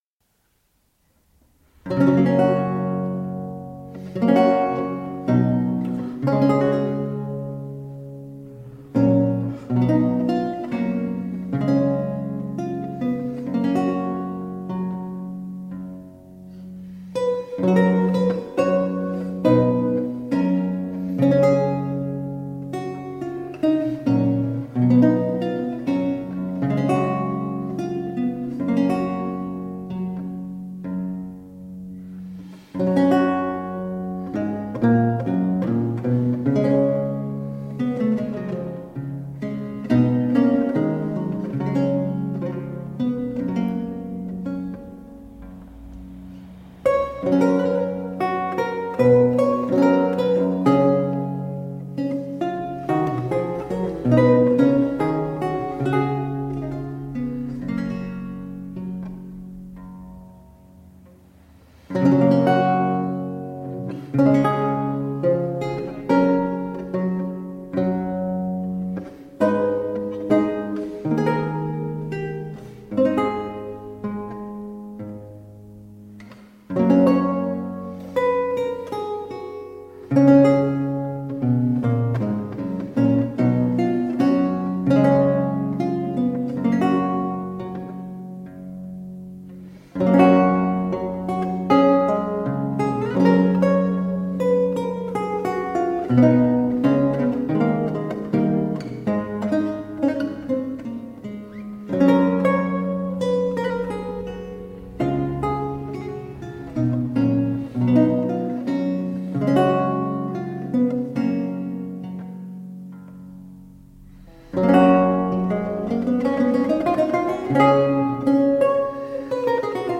Reflective, historically-informed performance on the lute.
Classical, Renaissance, Baroque, Instrumental